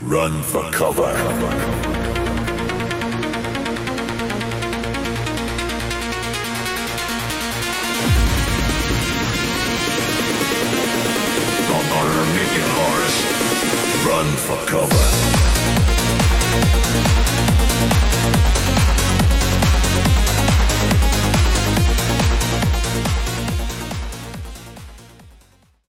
Style : Trance, Eurodance